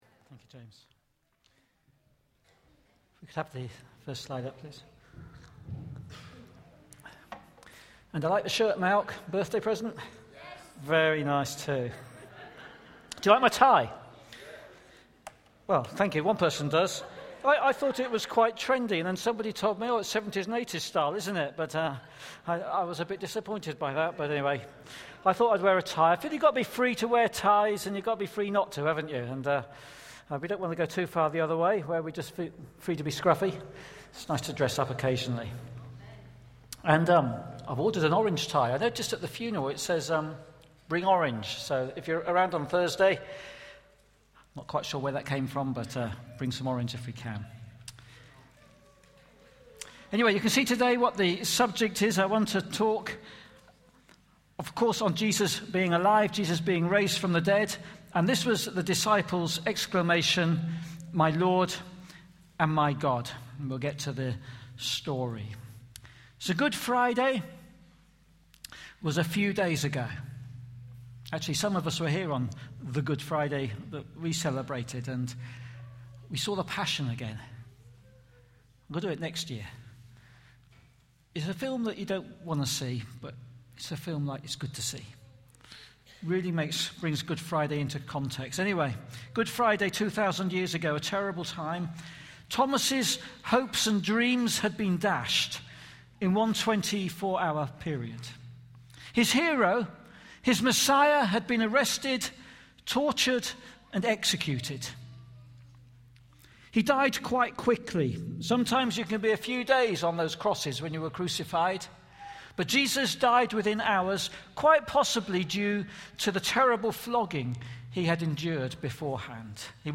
Listen back to all Sunday morning talks below.